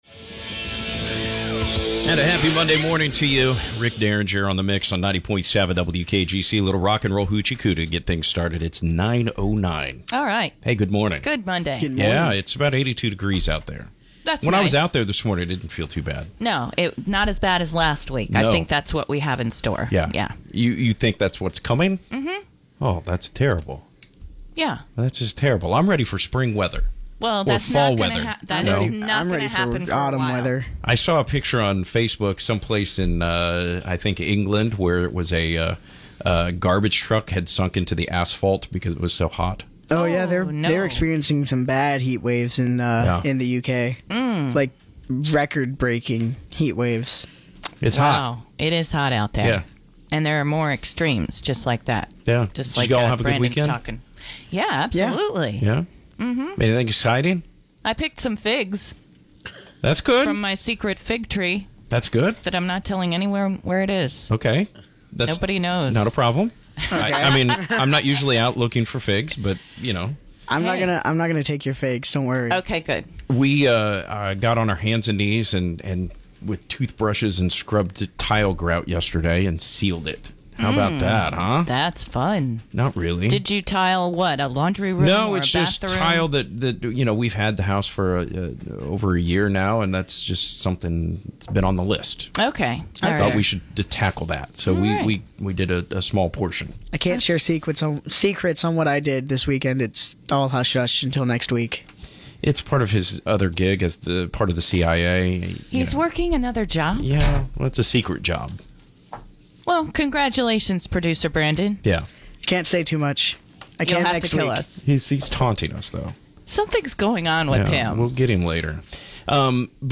We start the week off on The Morning Mix as Panama City Growing Strong is welcomed into Studio A! We talk development, have a Vinyl Play, and more!